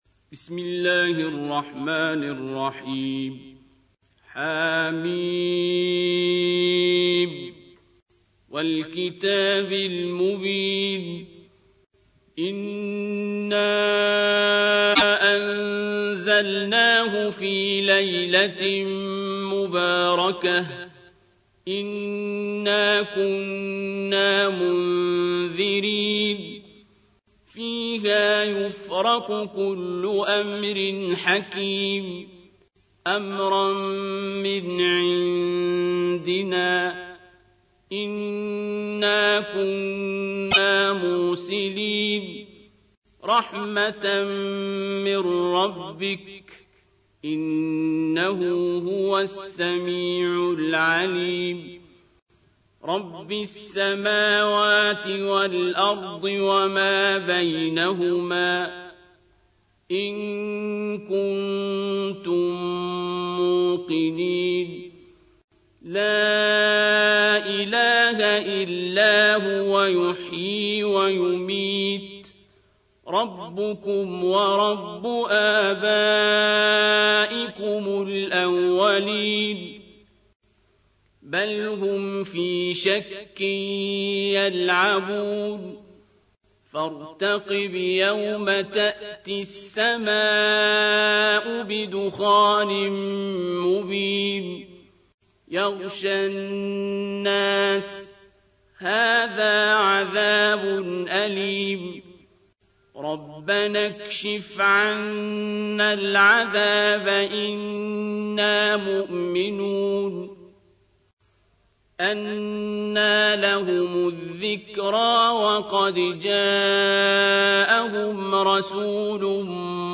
تحميل : 44. سورة الدخان / القارئ عبد الباسط عبد الصمد / القرآن الكريم / موقع يا حسين